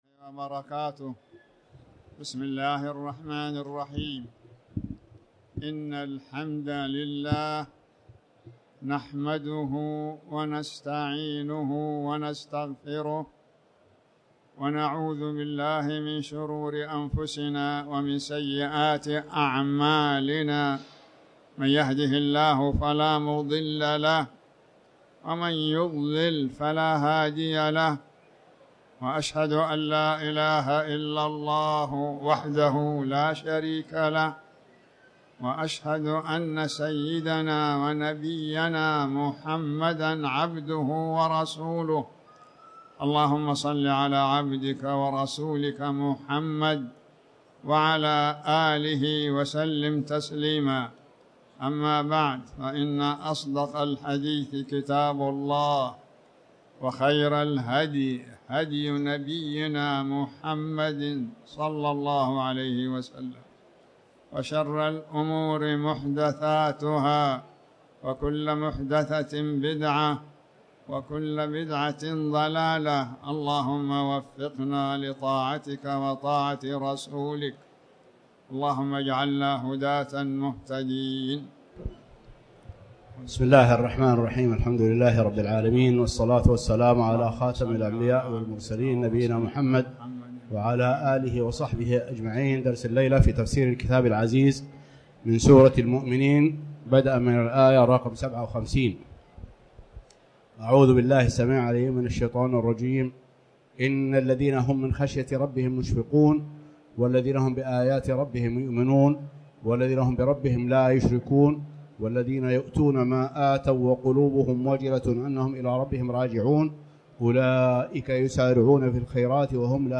تاريخ النشر ١٠ ذو القعدة ١٤٣٩ هـ المكان: المسجد الحرام الشيخ